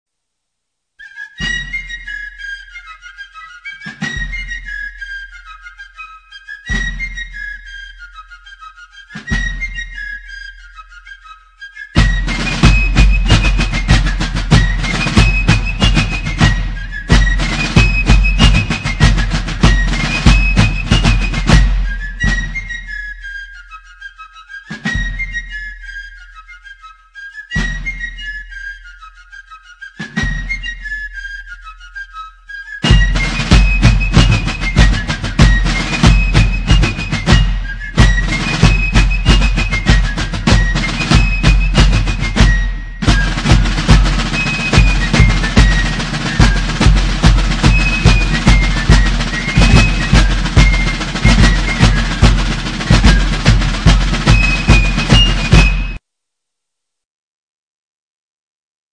Trommelsignale
(Drummer & Fifer)
Wichtige Trommelsignale und ein paar Rhythmen -